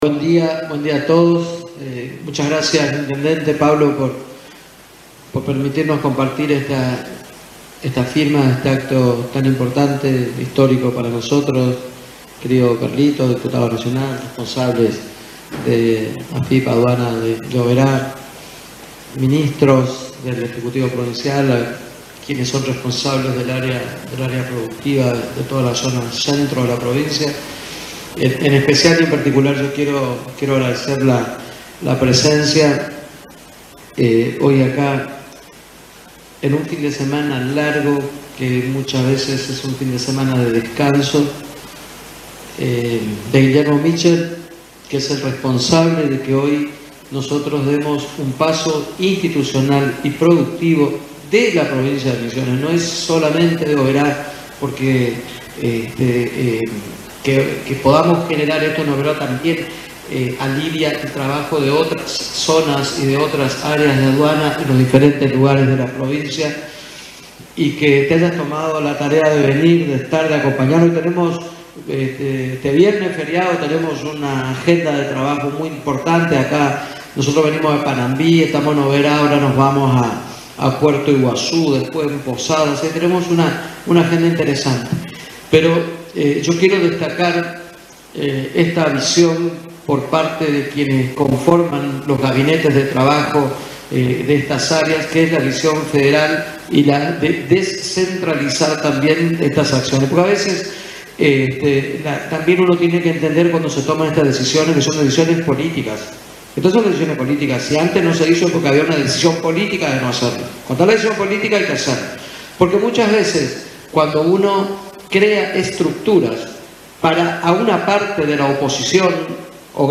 Durante el acto inaugural, Herrera Ahuad agradeció la presencia de funcionarios provinciales por estar presentes en “dar un paso institucional y productivo de la provincia de Misiones, no es solamente de Oberá”, expresó.
OSCAR-HERRERA-AHUAD-GOBERNADOR-DE-MISIONES-OBERA-INAUGURAN-OFICINAS-DE-ADUANA-EN-OBERA-MOL-TV.mp3